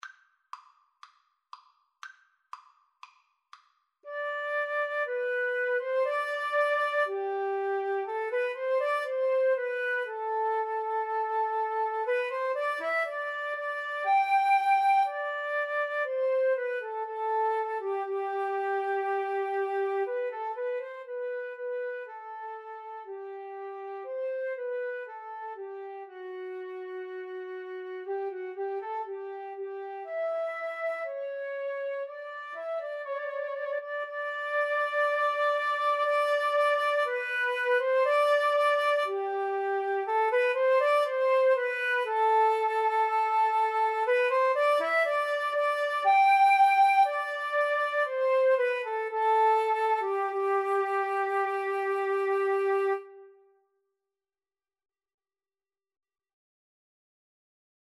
4/4 (View more 4/4 Music)
Allegro = c. 120 (View more music marked Allegro)
Classical (View more Classical Guitar-Flute Duet Music)